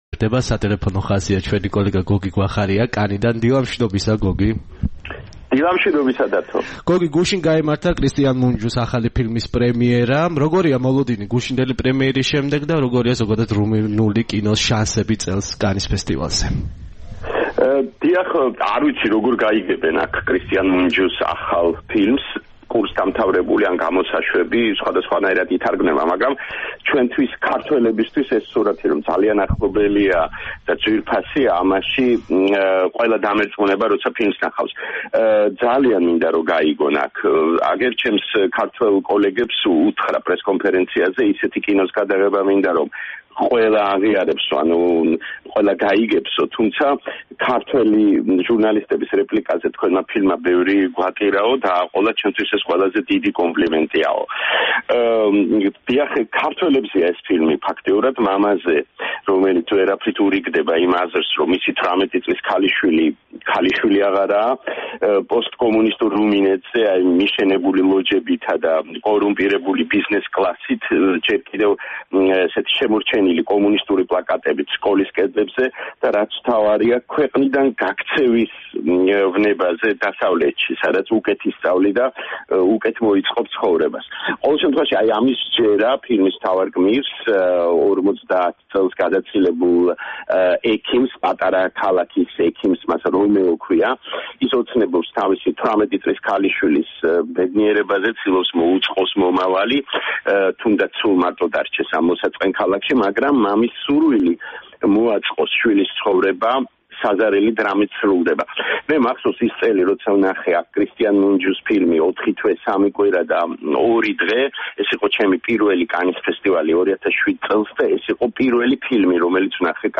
ჩვენი სპეციალური კორესპონდენტი გვიამბობს კანის კინოფესტივალის პროგრამაზე და იმაზე, თუ როგორ ჩაიარა მორიგმა საკონკურსო ჩვენებამ.